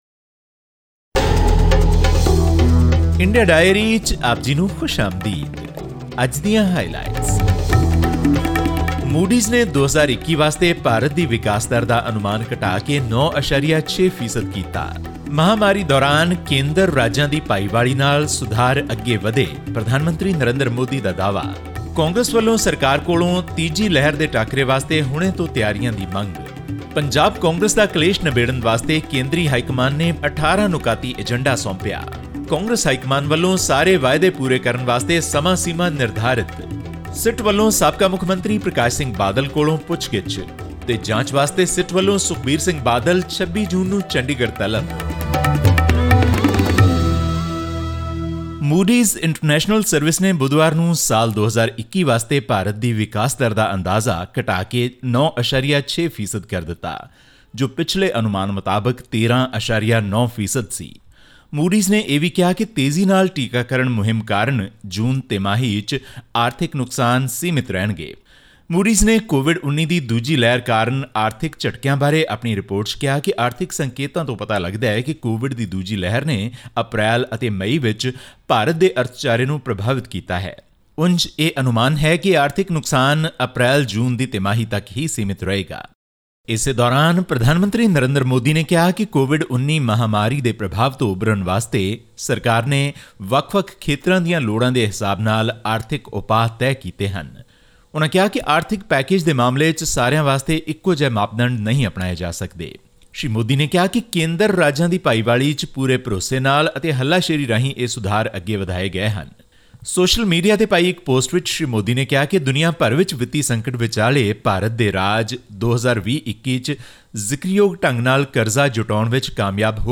Moody's Investor Service, the global provider of credit ratings, has slashed India's growth forecast to 9.6% for 2021, from its earlier estimate of 13.9%. All this and more in our weekly news update from India.